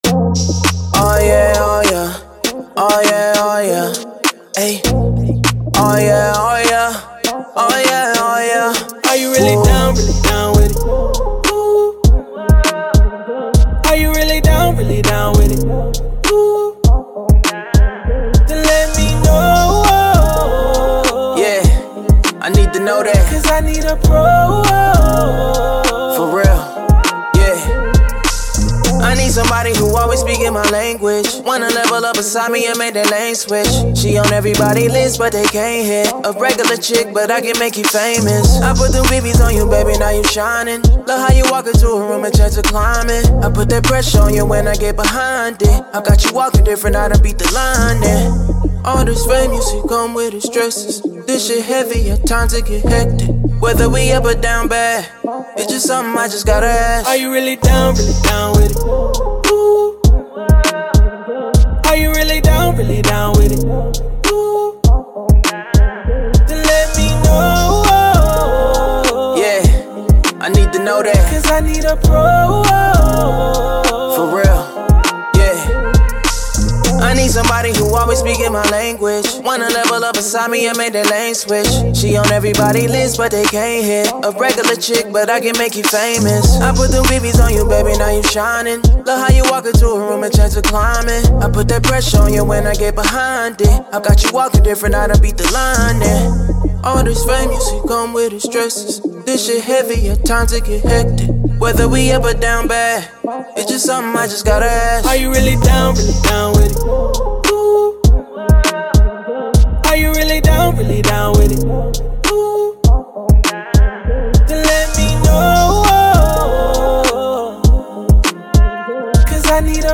R&B
F Minor